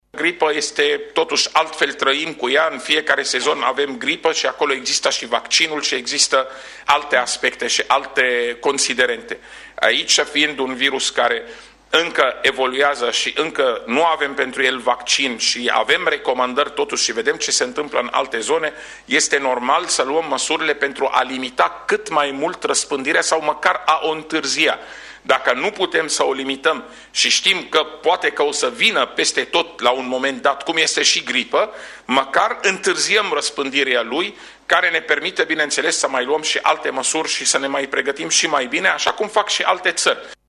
Într-o conferință de presă susținută în Capitală, secretarul de stat în Ministerul de Interne, Raed Arafat, a arătat că nu a mai existat o astfel de situație în istoria recentă și a făcut o comparație cu epidemiile de gripă: